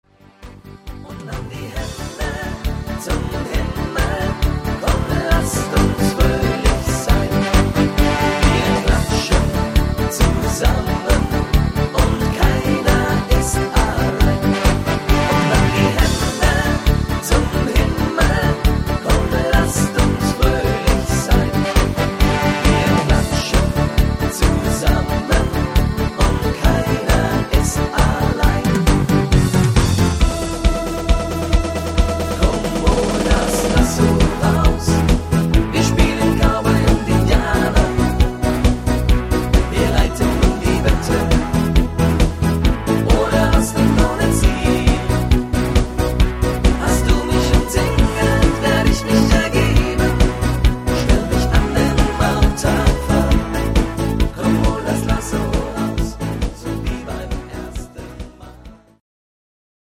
9382  Info   Wiesn-Hitmix (gut zu singende Tonarten